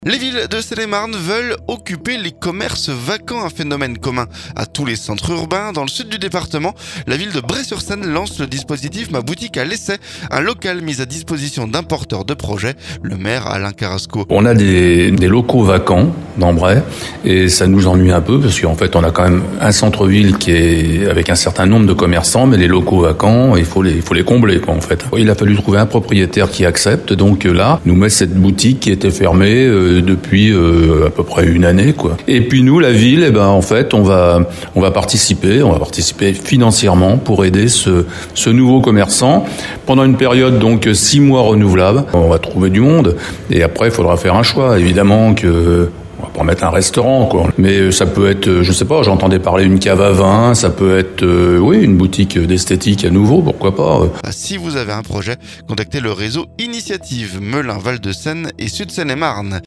Dans le sud du département, la ville de Bray-sur-seine lance le dispositif Ma boutique à l'essai. Un local mis à disposition d'un porteur de projet. Le maire, Alain Carrasco.